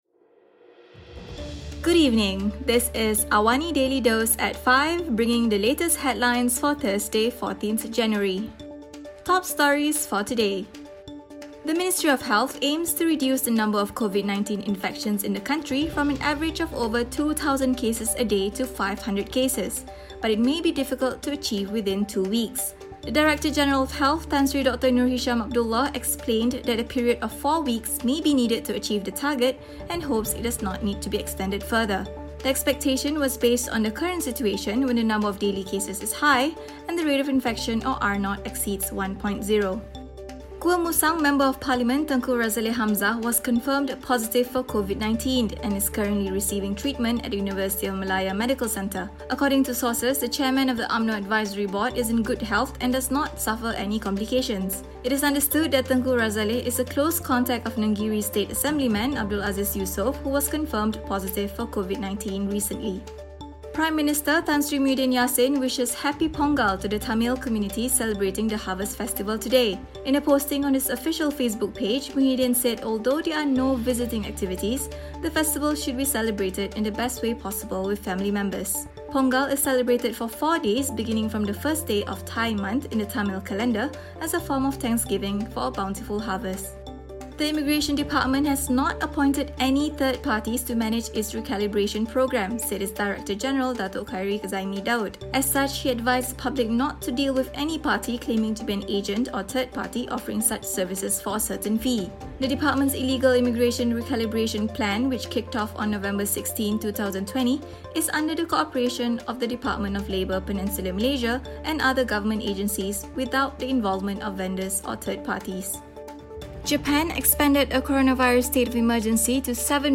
Also, South Korea’s Supreme Court on Thursday upheld a 20-year prison term for former President Park Geun-hye over bribery and other crimes as it wrapped up a historic corruption case. Listen to the top stories of the day, reporting from Astro AWANI newsroom — all in 3 minutes.